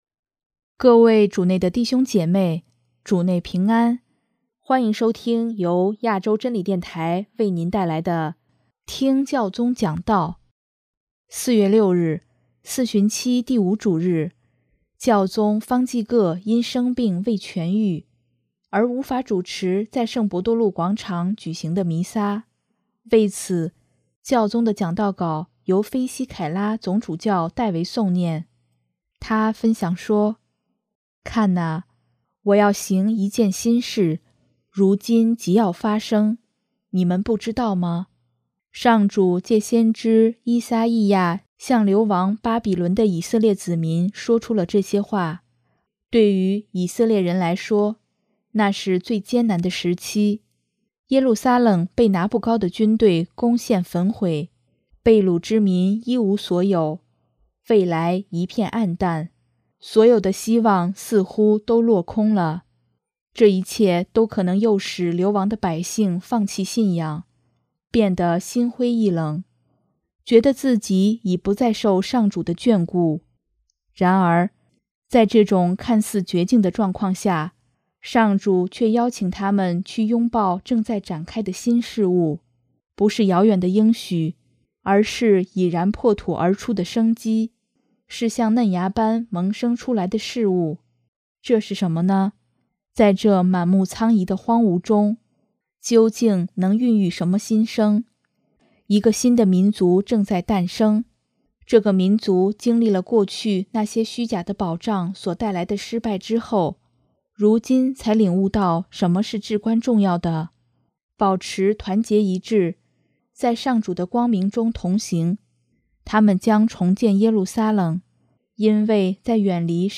首页 / 新闻/ 听教宗讲道
为此，教宗的讲道稿由菲西凯拉（H.E. MSGR. RINO FISICHELLA）总主教代为诵念。